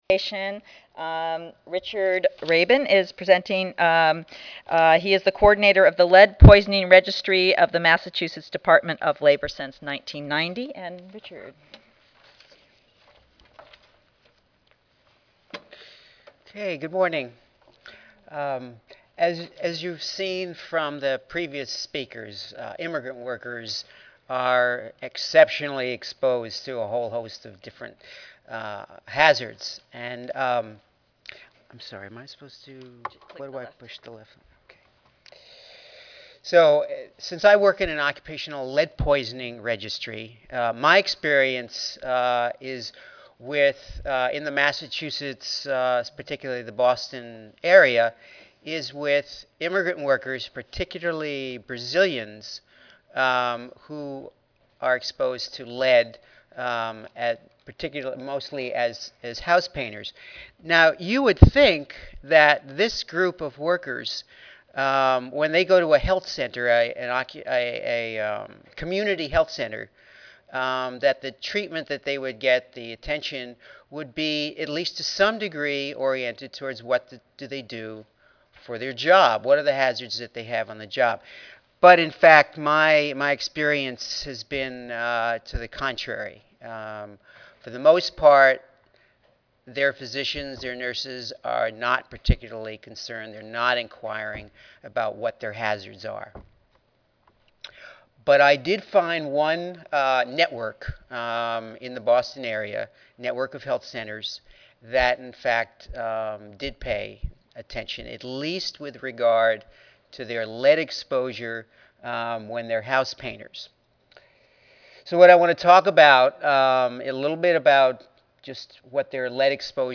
3144.0 Occupational Health Disparities Institute: Addressing Issues of Minority and Immigrant Workers Monday, October 27, 2008: 10:30 AM Oral This session includes presentations from a variety of programs/projects that are attempting to address occupational health issues of ethnic minority and/or immigrant workers.